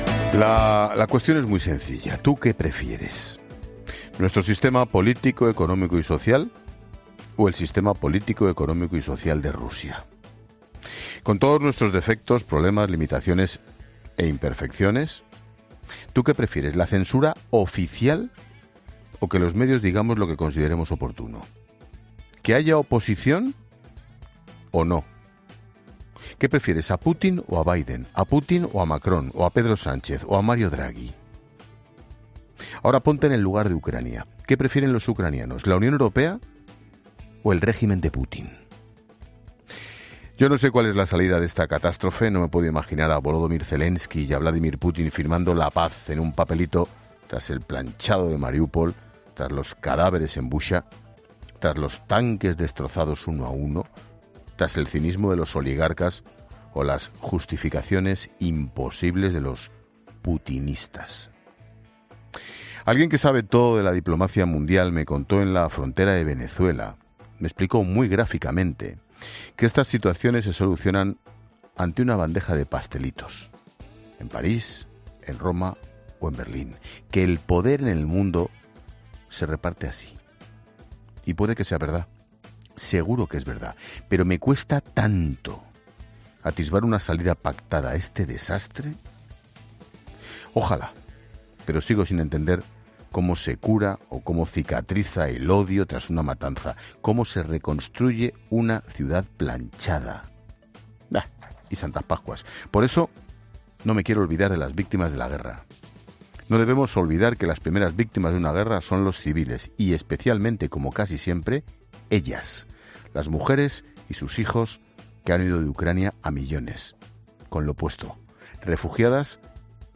Monólogo de Expósito Ángel Expósito: "Es sencillo.